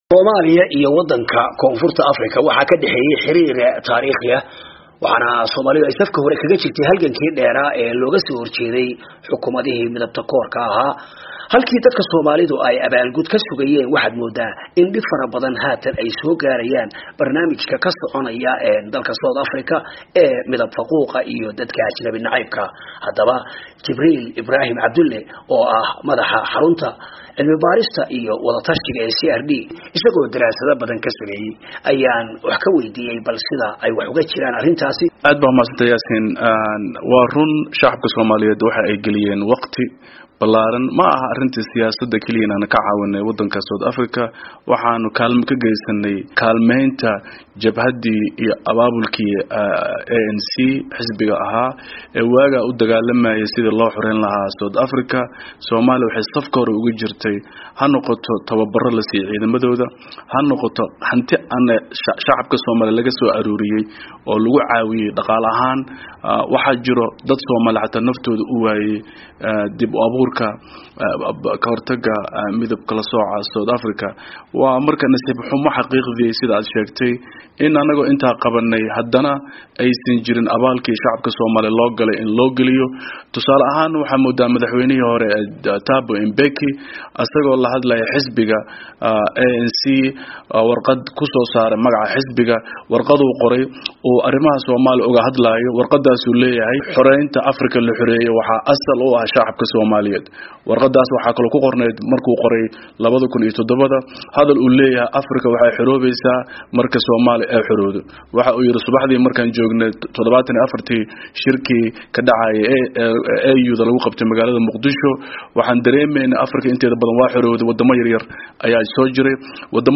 Falanqeyn: Koonfur Afrika, Maanta iyo Shalay